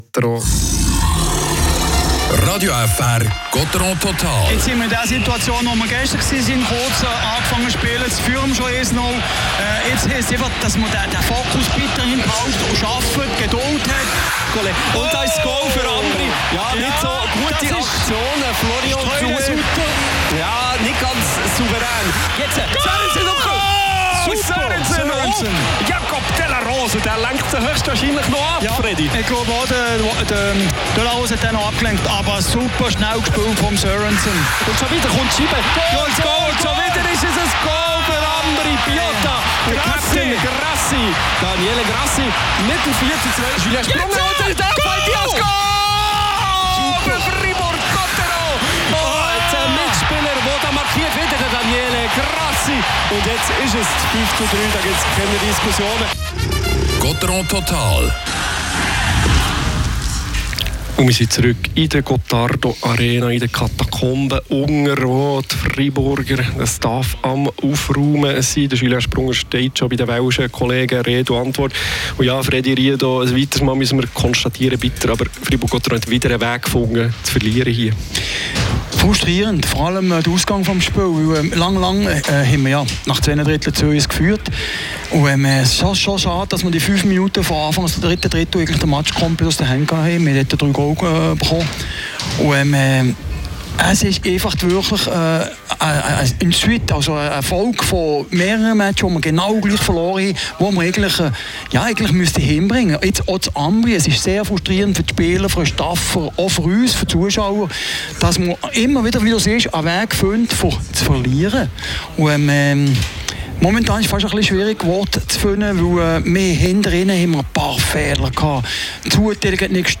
Interviews mit Julien Sprunger und Samuel Walser